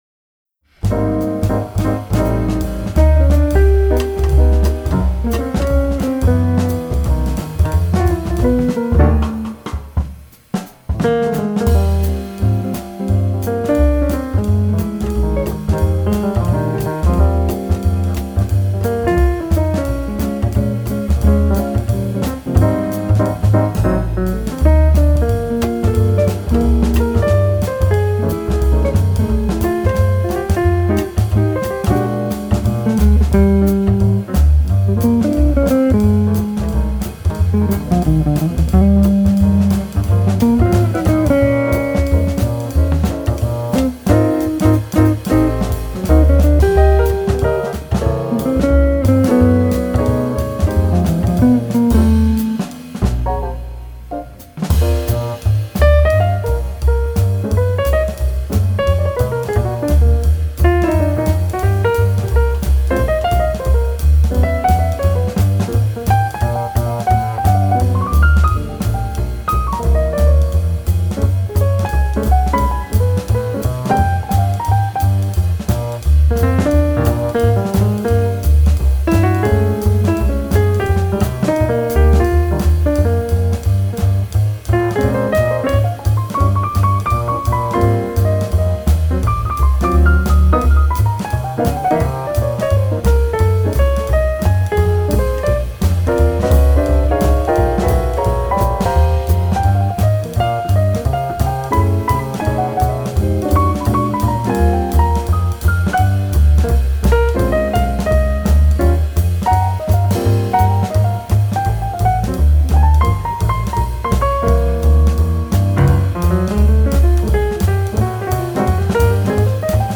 FILE: Jazz